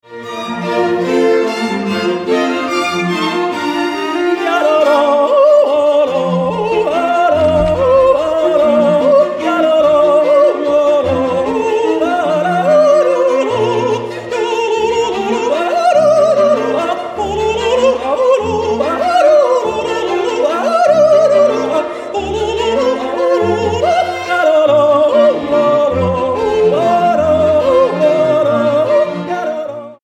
für Jodel Solo und Streichquartett
Beschreibung:Kammermusik
Besetzung:Jodel Solo, Streichquartett
Uraufführung